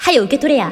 Worms speechbanks
Fireball.wav